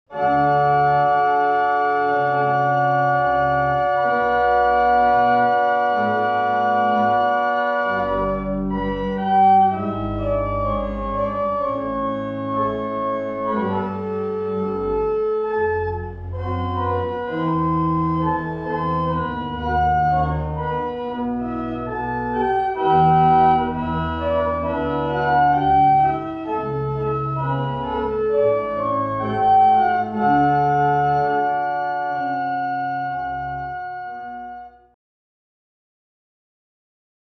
Aux claviers de l'orgue de la Synagogue de Nazareth (ou au piano)